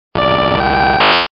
Archivo:Grito de Articuno.ogg